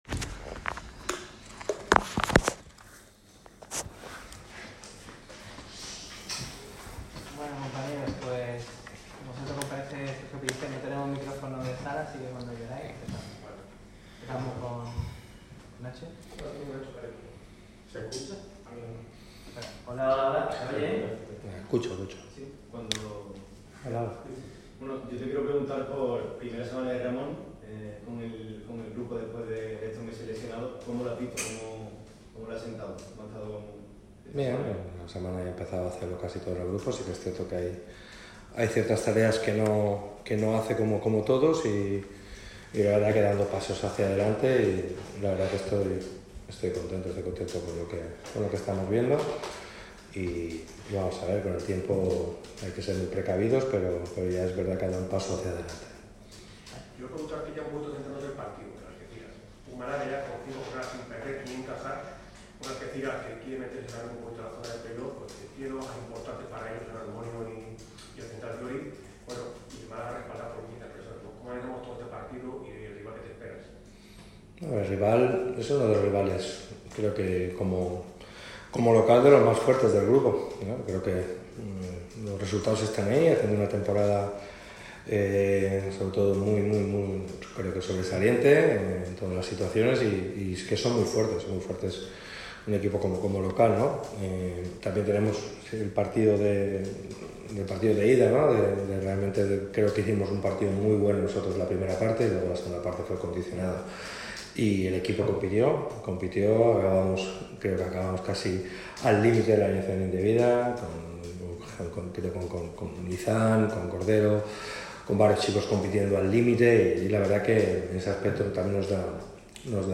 ha comparecido en la sala de prensa 'Juan Cortés', del estadio de La Rosaleda, con motivo de la previa del partido frente al Algeciras